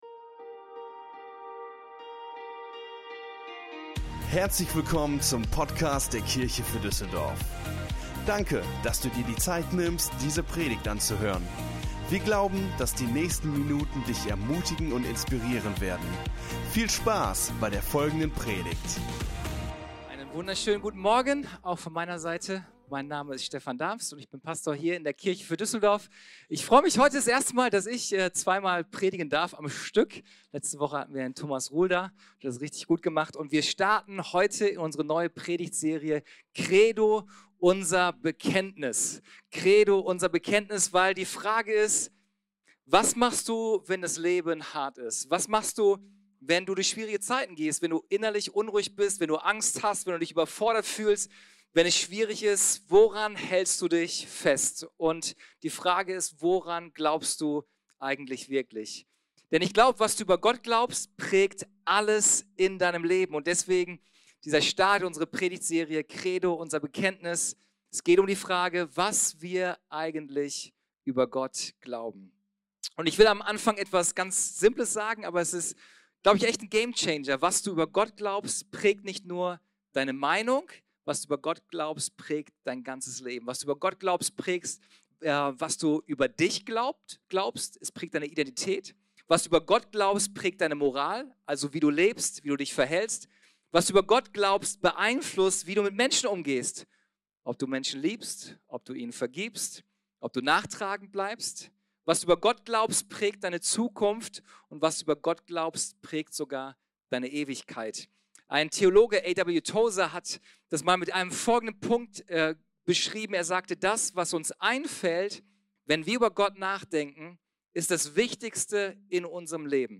In dieser Predigt entdecken wir, was es bedeutet, an einen Gott zu glauben, der nah, gut und voller Kraft ist.